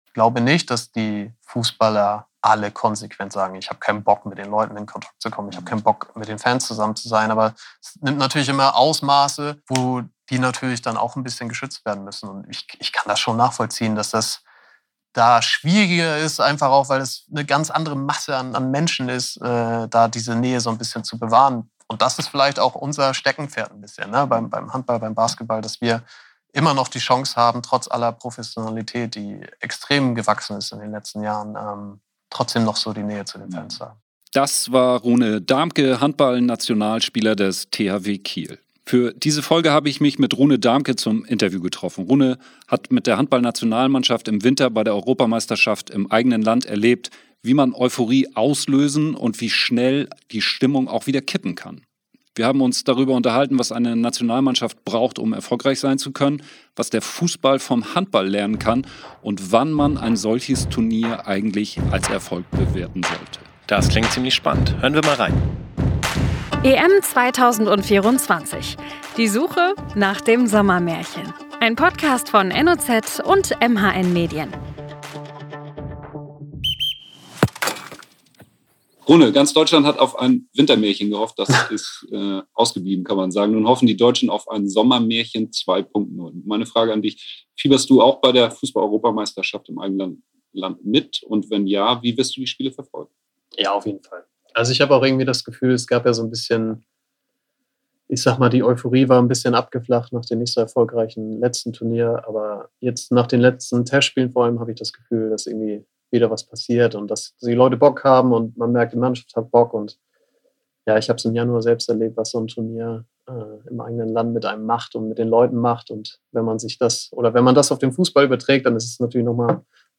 In Folge 4 des EM-Podcasts sprechen wir mit einem Handball-Europameister von 2016, was der Fußball von anderen Sportarten lernen kann Der langjährige Handball-Nationalspieler Rune Dahmke vom THW Kiel erzählt unseren Hosts, warum er überglücklich ist, Handballer zu sein.